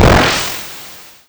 explode_b.wav